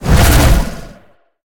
File:Sfx creature hiddencroc chase os 01.ogg - Subnautica Wiki
Sfx_creature_hiddencroc_chase_os_01.ogg